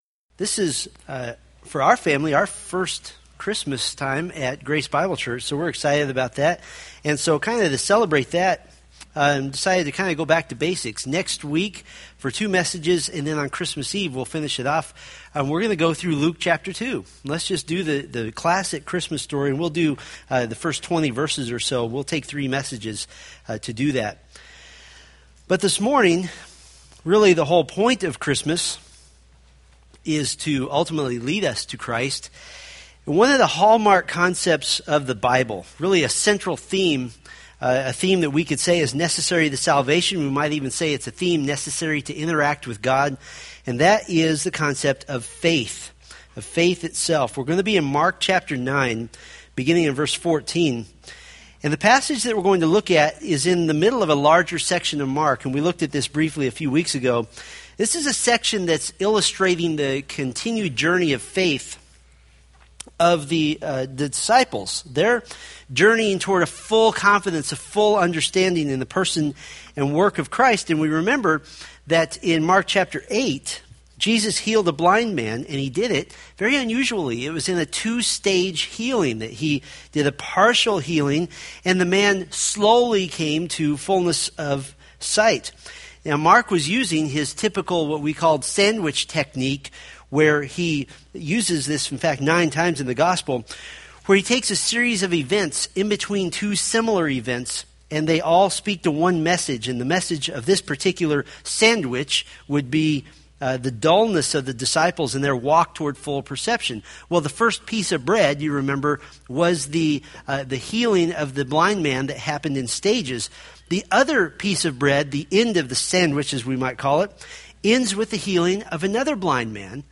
Mark Sermon Series